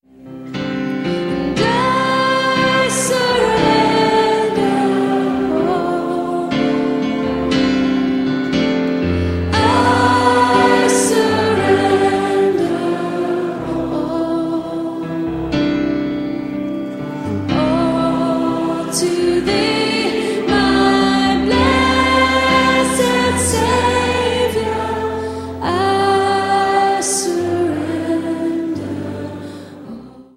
Diese Live-CD entfacht ein kreatives
• Sachgebiet: Praise & Worship